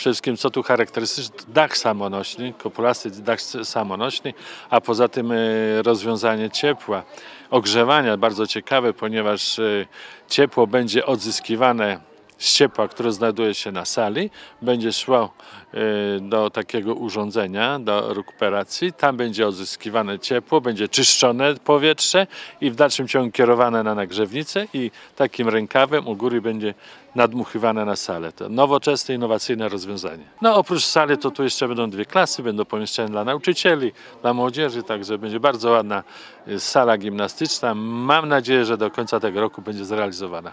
Uroczyste wmurowanie kamienia węgielnego pod budowę nowej hali sportowej odbyło się w środę (12.04) w podsuwalskim Starym Folwarku.
O innowacyjności inwestycji opowiedział Radiu 5 Tadeusz Chołko, wójt gminy Suwałki.